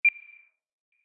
button-hover.mp3